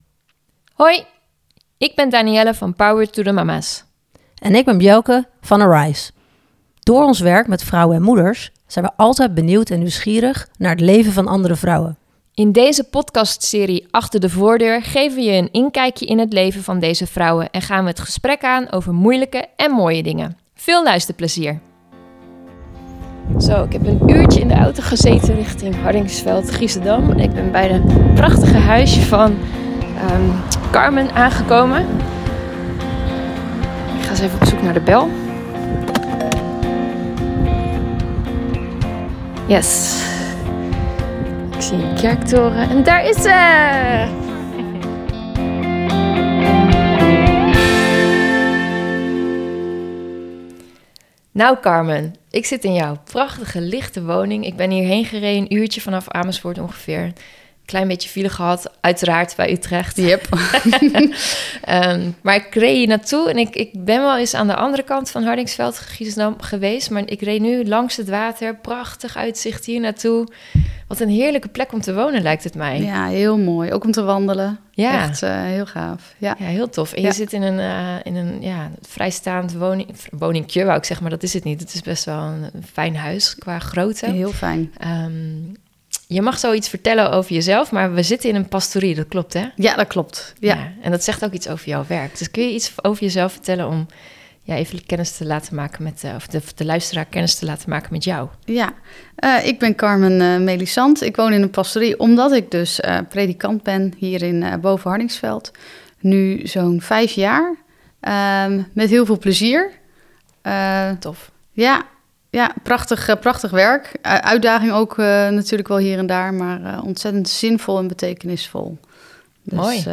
Inspirerende podcasts over het leven als gelovige moeder en vrouw in een wereld die nooit hetzelfde is. Je luistert naar gesprekken met andere moeders in de serie Achter de voordeur, hoort motiverende woorden of een gesprek met een professional.